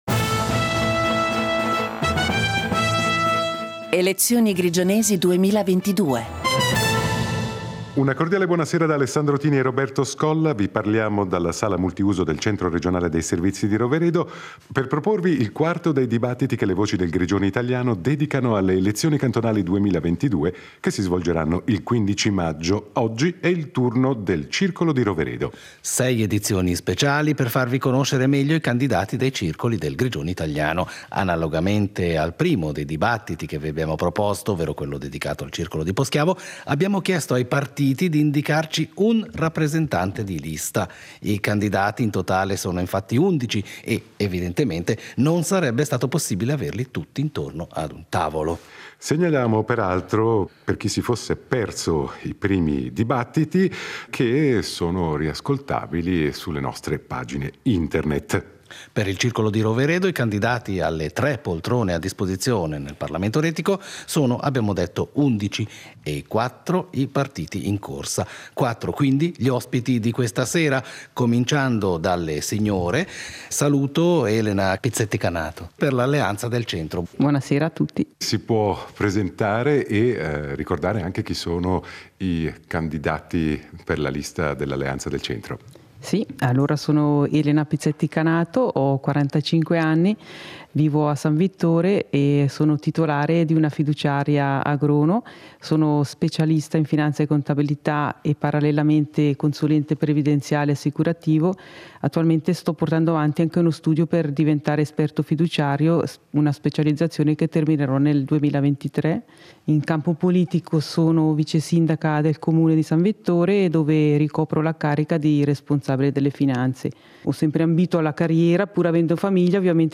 Dibattito in vista delle elezioni retiche del 15 maggio 2022